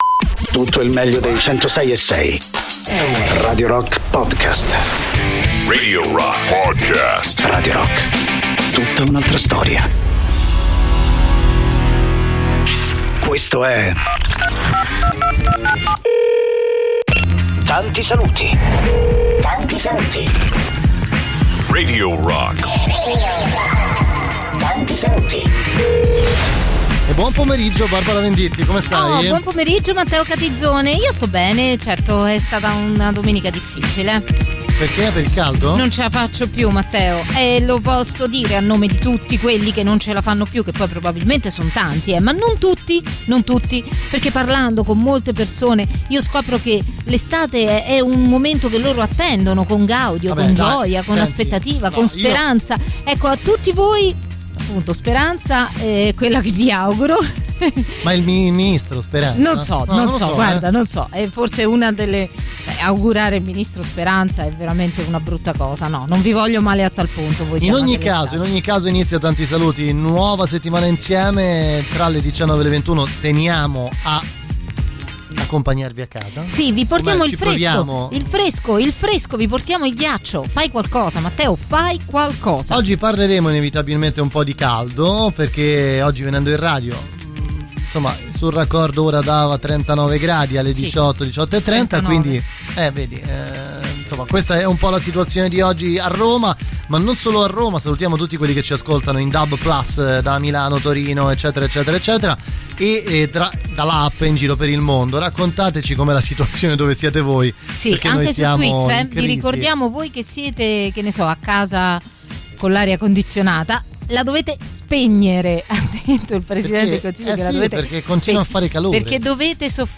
in diretta dal lunedì al venerdì